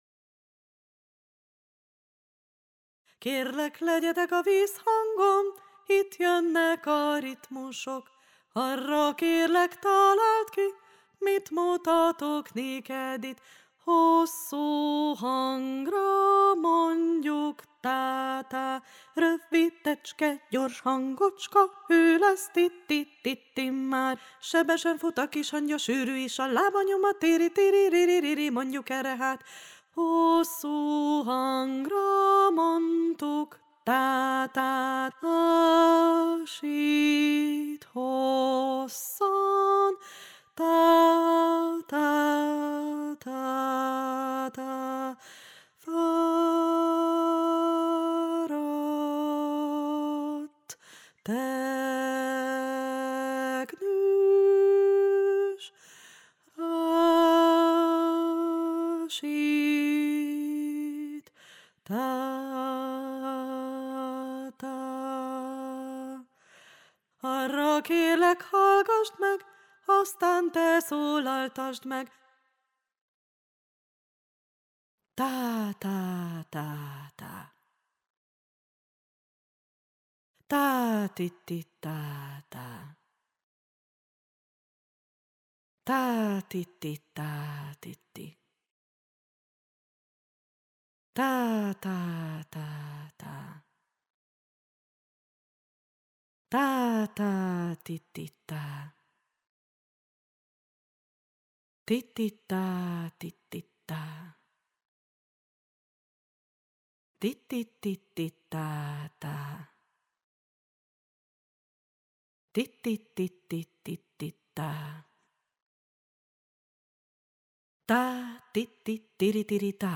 RITMUSÉRTÉK mondóka_Köszöntő és alap_karbantartó játék_Mindenkinek